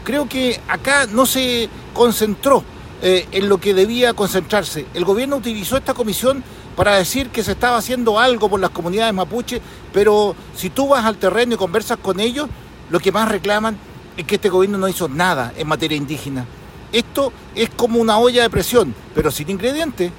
Otro de los parlamentarios de RN, el diputado Miguel Mellado, señaló que el Gobierno utilizó la Comisión para decir que estaba haciendo algo por las comunidades mapuches.